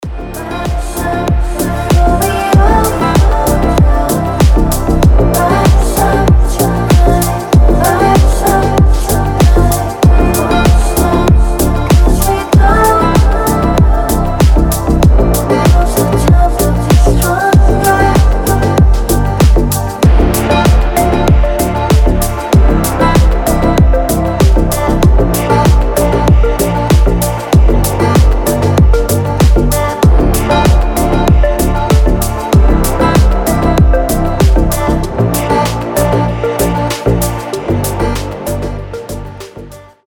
• Качество: 320, Stereo
deep house
атмосферные
chillout
кайфовые
расслабляющие